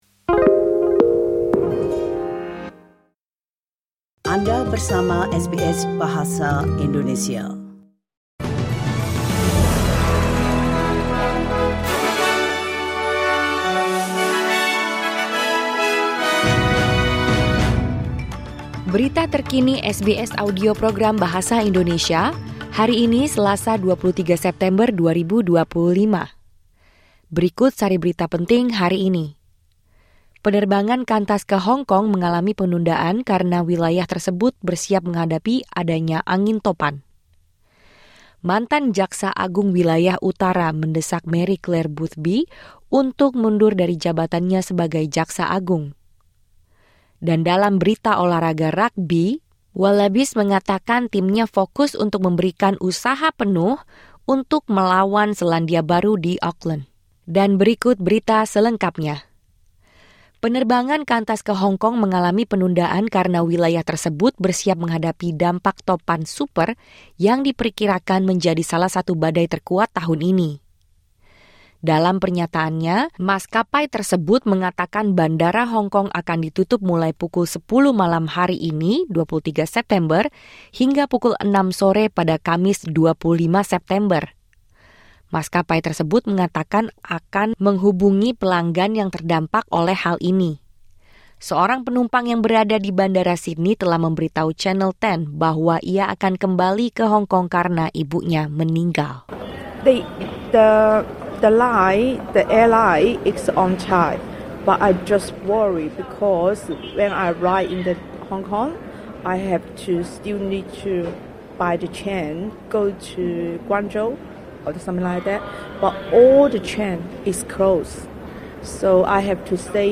Berita Terkini SBS Audio Program Bahasa Indonesia – 23 September 2025.